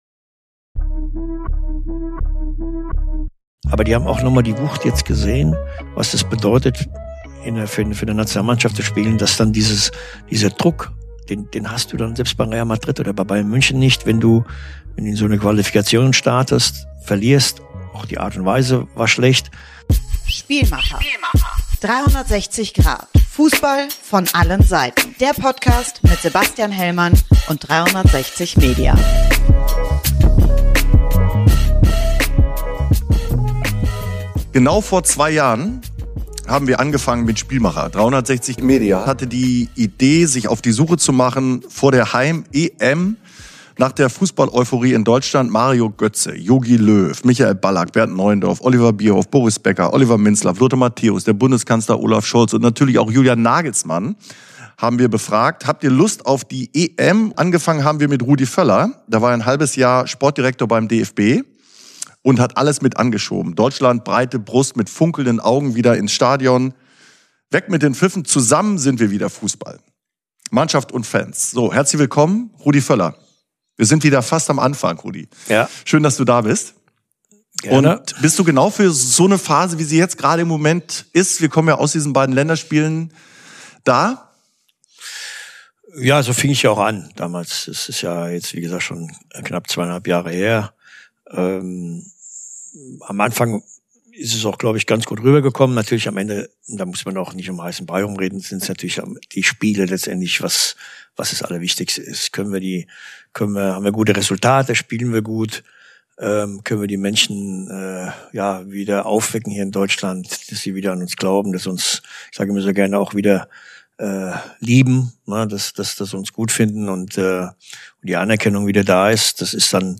Jetzt sitzt der DFB-Sportdirektor wieder mit Sebastian Hellmann zusammen, im neuen Campus von Sports360 in Köln. Sie blicken zurück auf eine turbulente Länderspielpause, sprechen über Fan-Reaktionen, emotionale Ausbrüche in der Umkleidekabine, auf Kampfansagen und deren Beurteilungen.